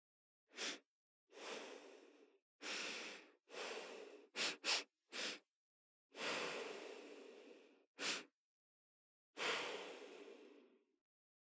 Звуки вынюхивания аромата
женщина вдыхает аромат духов